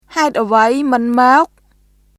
[ハエット・アヴァイ・ムン・モーク　haet ʔəvəi mɯn mɔ̀ːk ]